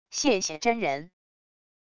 谢谢真人wav音频